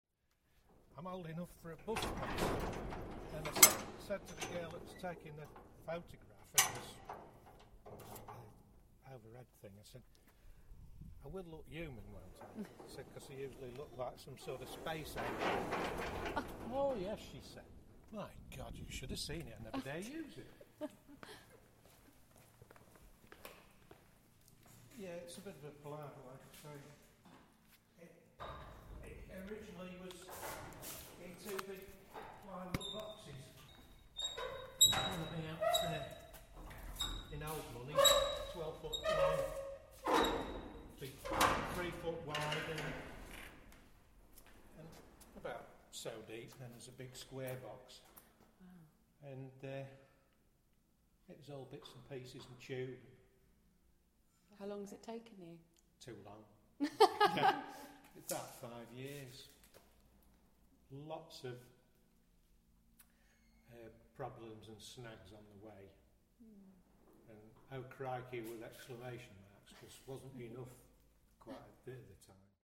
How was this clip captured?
Being shown around the hangers and one particular plane, a labour love, that has just had its first flight.